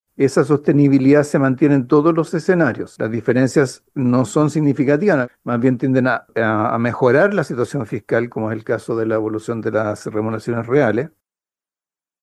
Por su parte, el ministro Marcel afirmó que las observaciones del Consejo Fiscal Autónomo no incomodan al Gobierno, y que contribuyen a reforzar el compromiso con la responsabilidad fiscal.